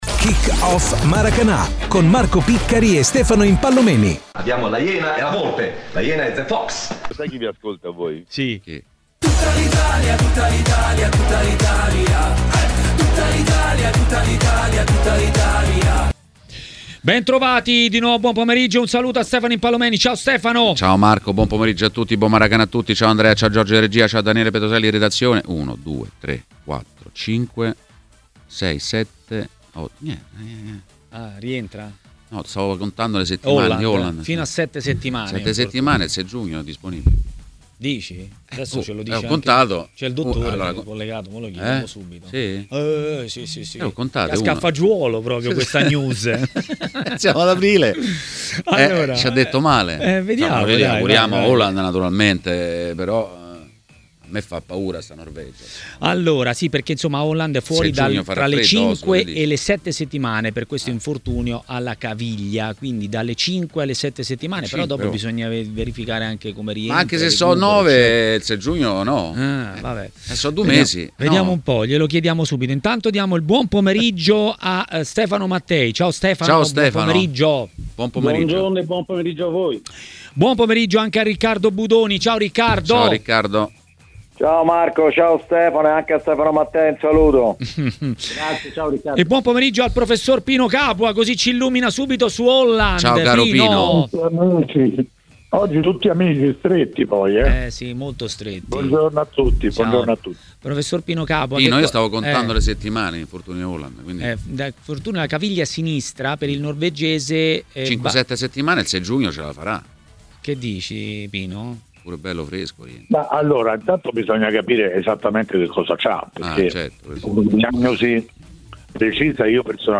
ha parlato dei temi del giorno a TMW Radio, durante Maracanà.